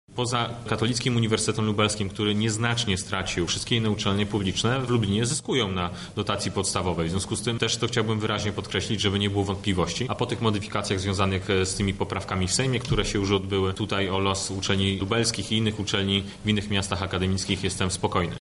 Na Politechnice odbyła się konferencja dotycząca pozycji uczelni regionalnych w kontekście Konstytucji dla Nauki.
Należy podkreślić że jest to silny ośrodek akademicki – mówi Piotr Müller podsekretarz stanu Ministerstwa Nauki